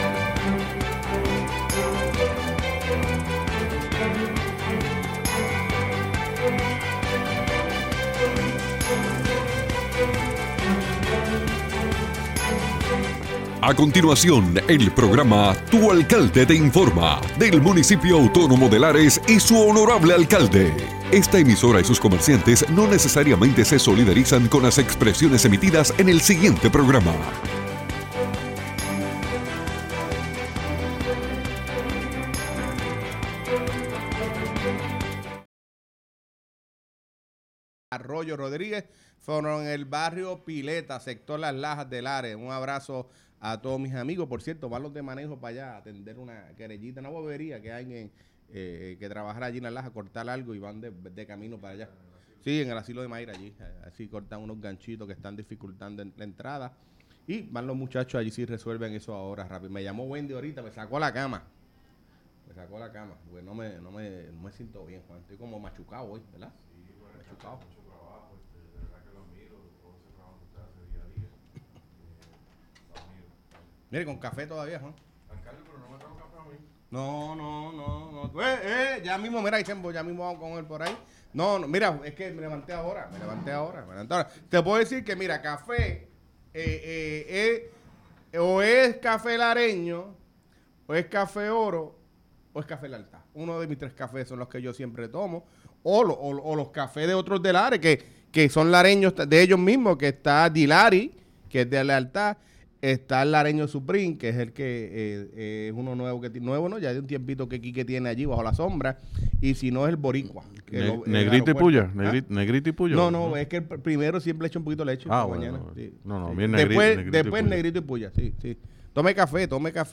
El honorable alcalde de Lares, Fabián Arroyo, junto a su equipo de trabajo nos informan sobre las novedades del municipio.